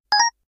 name_input_accept.wav